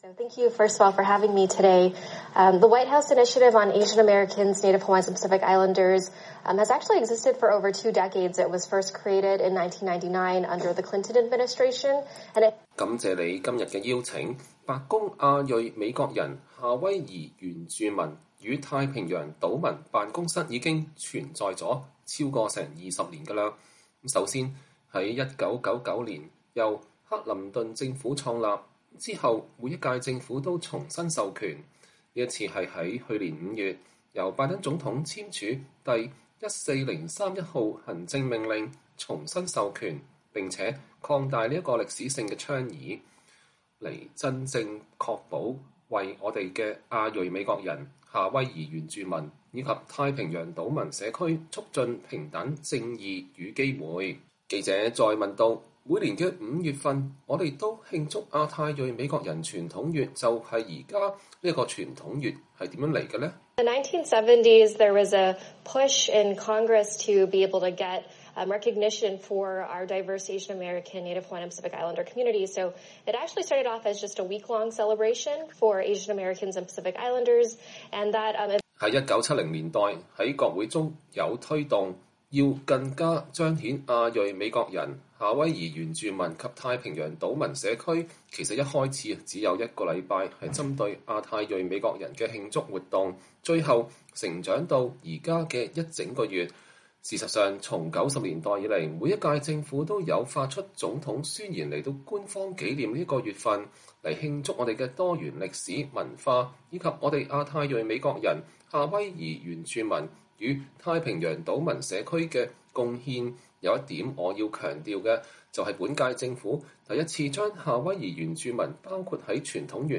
VOA專訪: 專訪白宮亞太裔美國人辦公室執行主任克莉絲托･卡艾伊